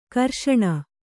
♪ karṣaṇa